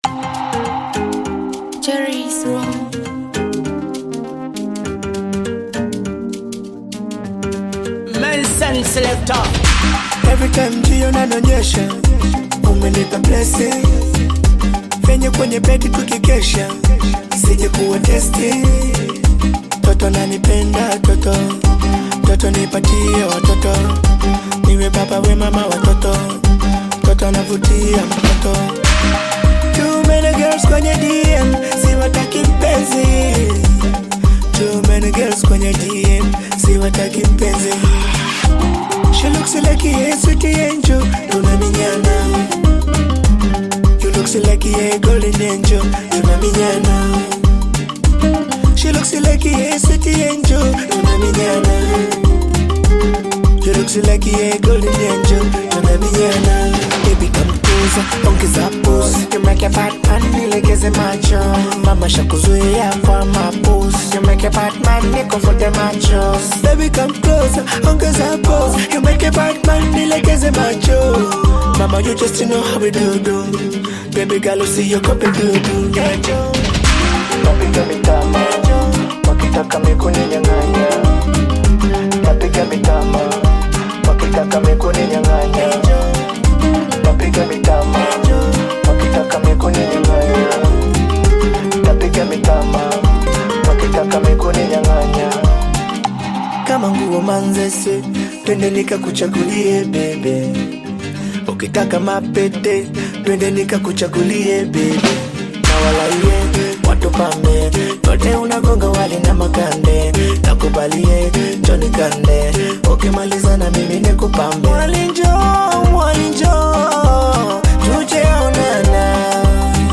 Yet another song from Tanzanian bongo flava artist singer
African Music